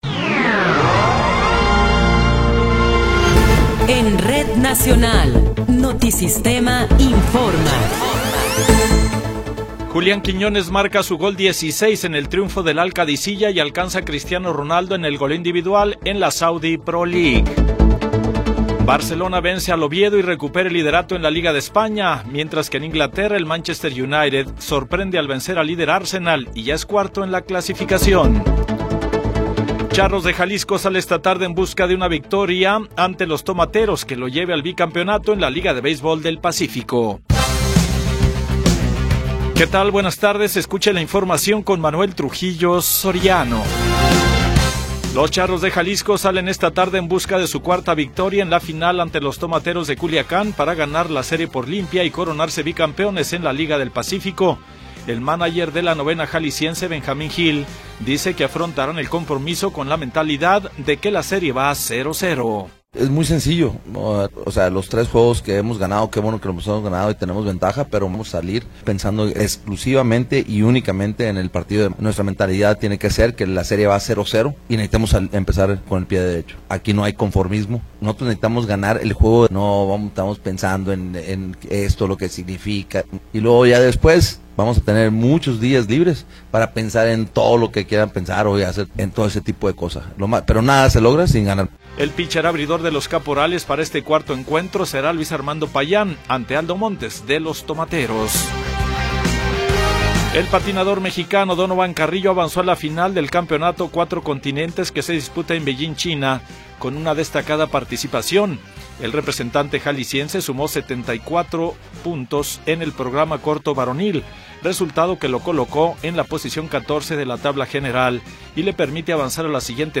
Noticiero 14 hrs. – 25 de Enero de 2026